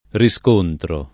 riscontro [ ri S k 1 ntro ]